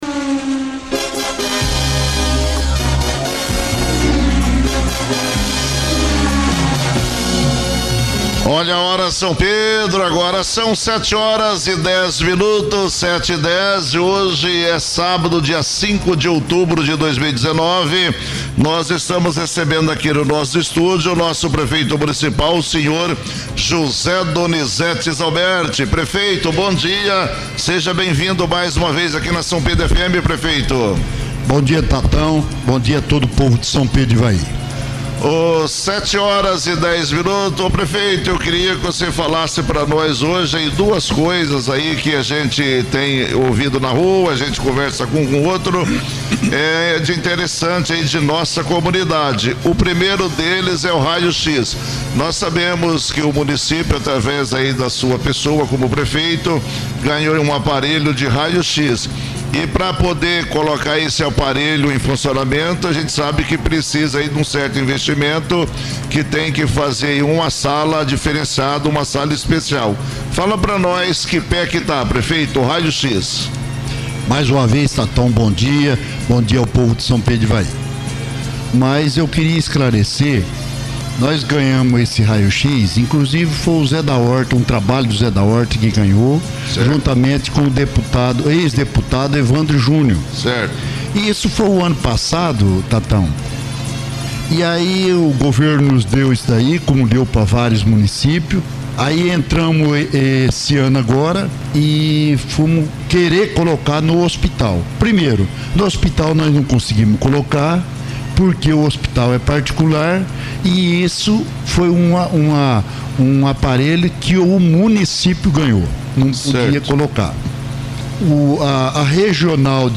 No rádio, prefeito explica situação sobre Raio-x e fala de outros assuntos; ouça
Entrevista para Rádio SPFM